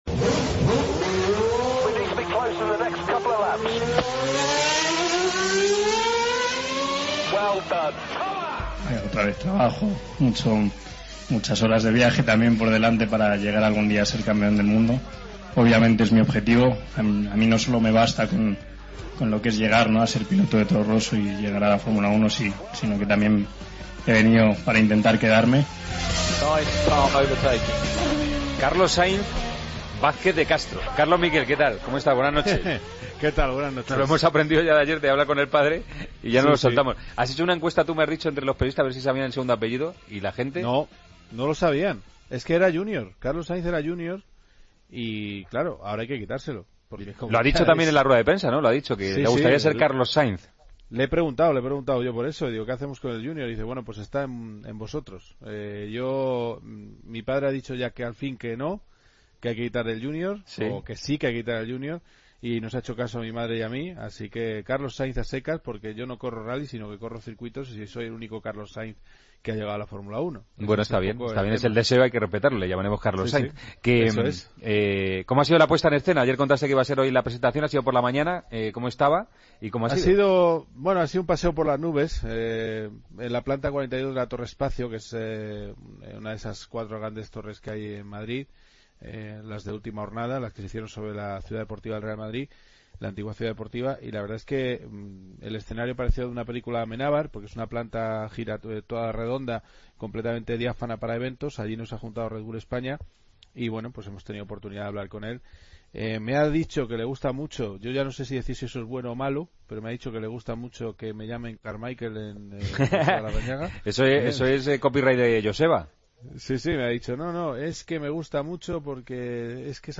Entrevista del día, con Gregorio Manzano. Agenda del resto de noticias.